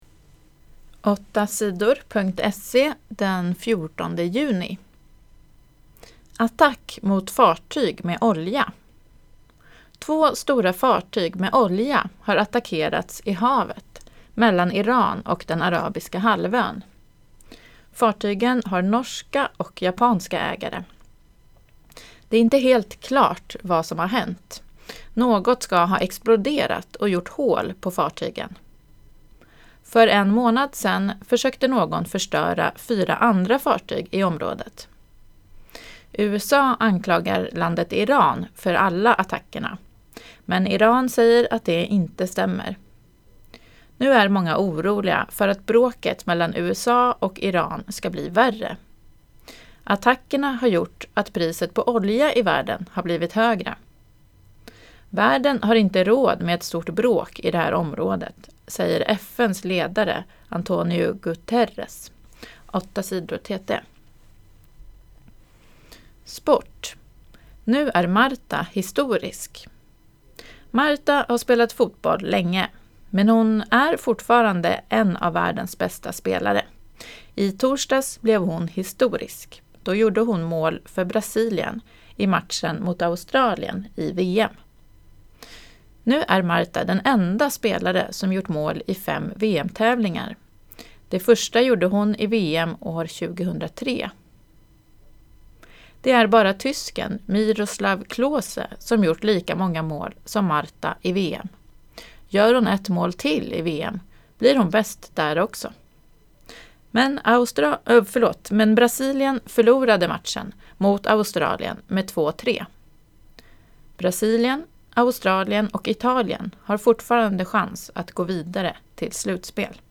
Inlästa nyheter den 14 juni 2019